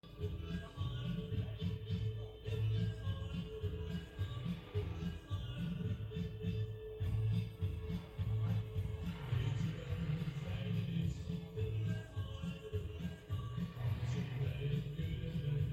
This is a short recording of the "music", taken from my bedroom...Yeah...
I'm so tired and this bass driven yodeling stuff is driving me nuts!